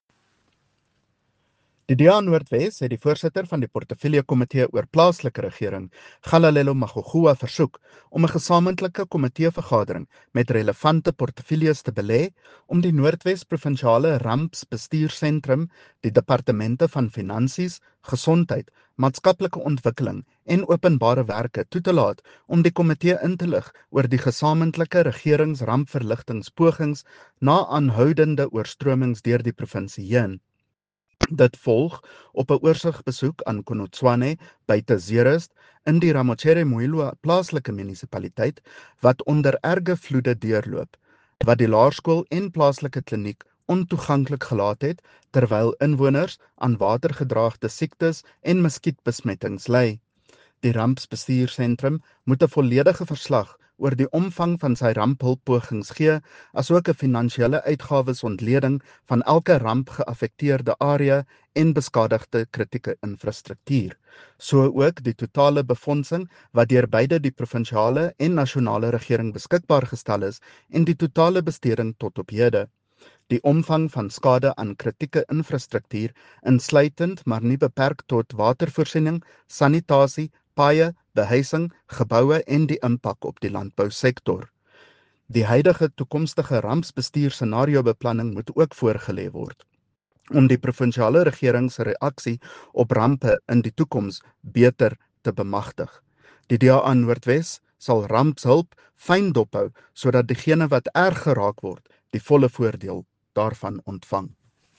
Note to Broadcasters: Please find attached soundbites in